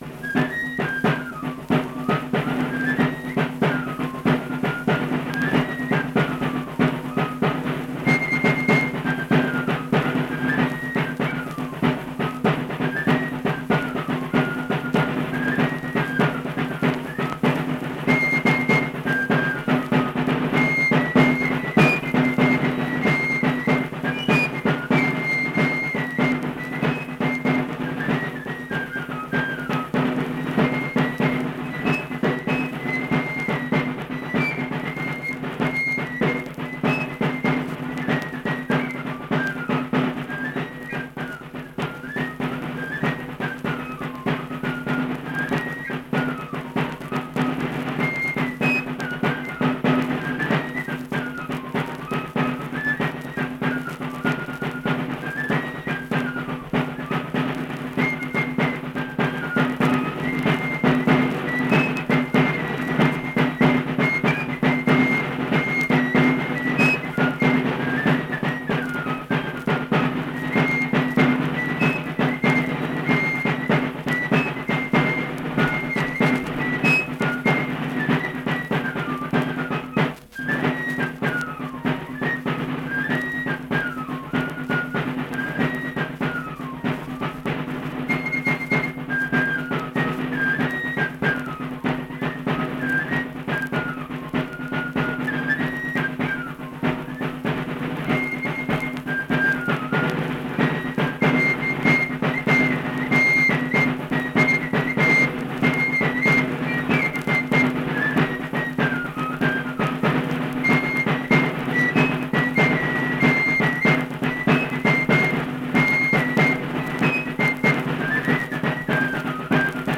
Accompanied fife and drum music
Performed in Hundred, Wetzel County, WV.
Instrumental Music
Fife, Drum